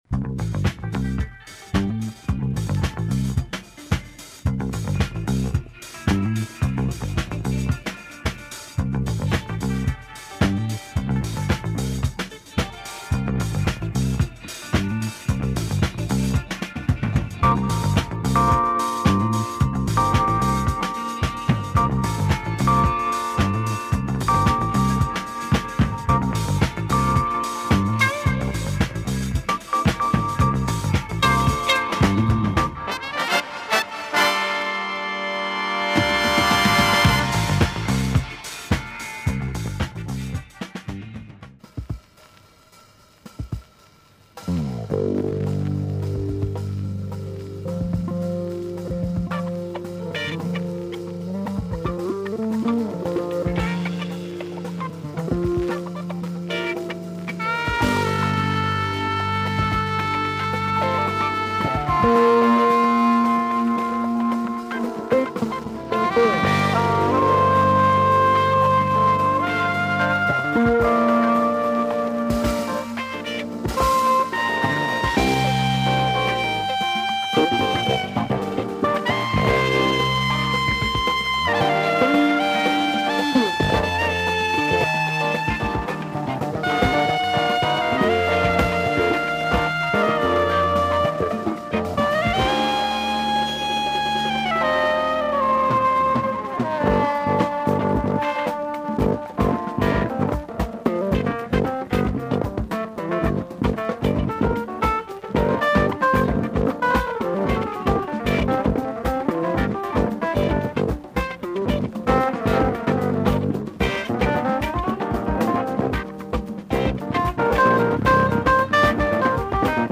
one uptempo track with an opening break
and another killer midtempo jazz funk